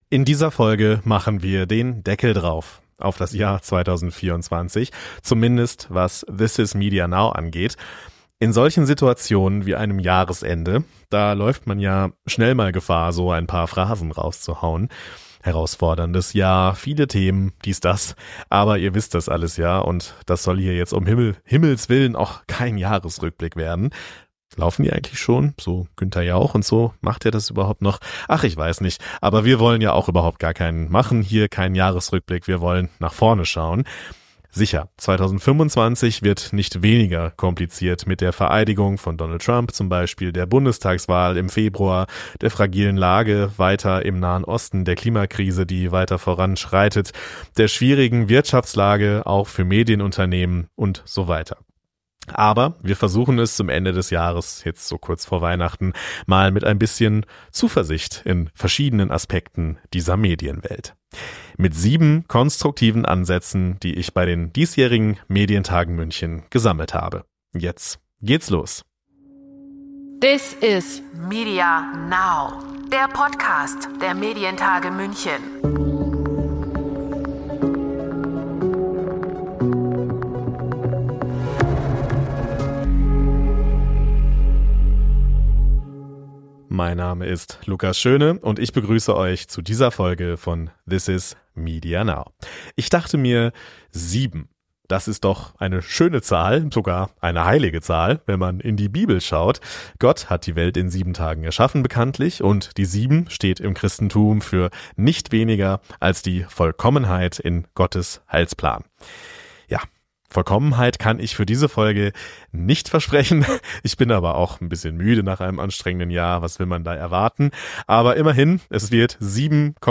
Es sind Expert:innen aus der Branche zu Gast, ihr hört Ausschnitte von Medienevents